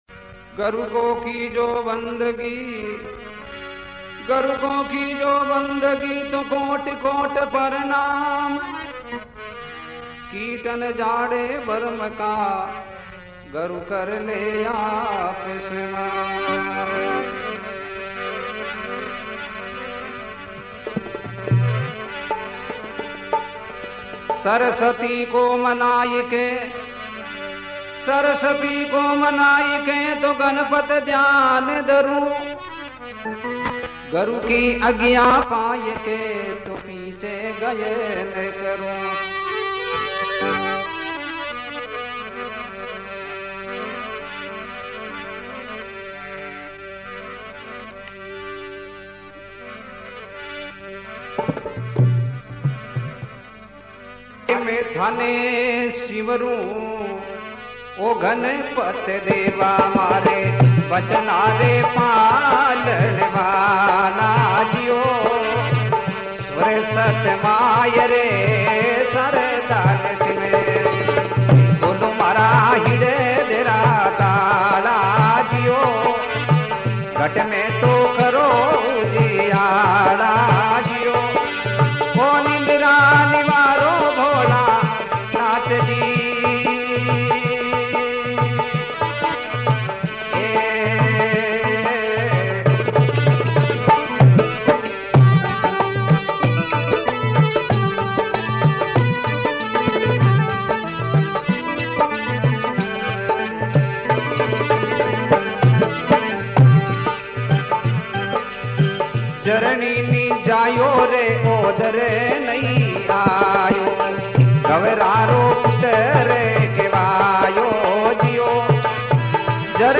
Rajasthani Songs
Satsang Bhajan